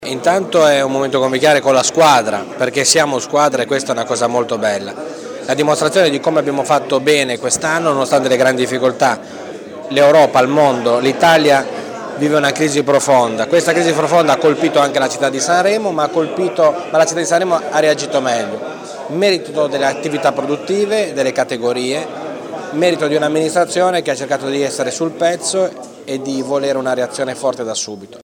Gli auguri del Sindaco di Sanremo cliccando